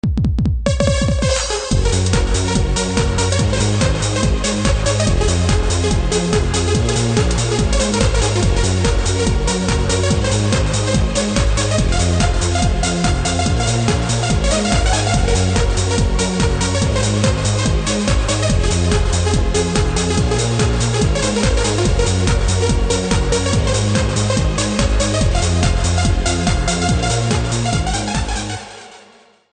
夏の炎天下の中のビーチをイメージして作ってみました。
作りこみが甘いし、最後は適当だし、音もまだリード・パッド・ベース・ドラムだけしか無いから派手さは足りないなぁ。
（データ量を小さくするために音質を落としてあります。）